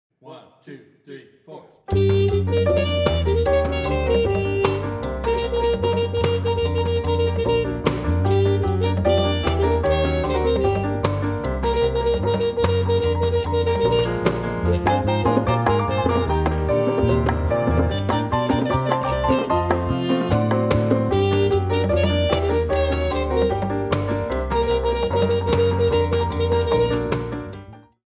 Trumpet, Electric Bass, Piano, Percussion